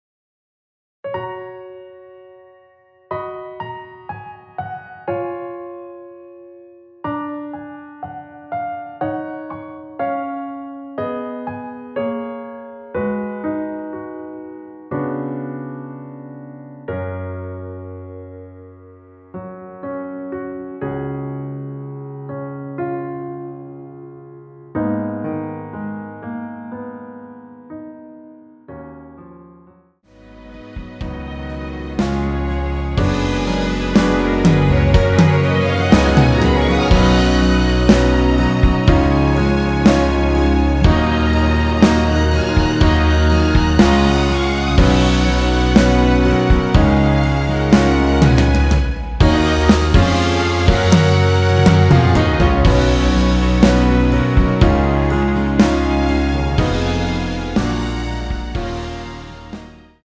(-1) 내린 MR 입니다.
F#
◈ 곡명 옆 (-1)은 반음 내림, (+1)은 반음 올림 입니다.
앞부분30초, 뒷부분30초씩 편집해서 올려 드리고 있습니다.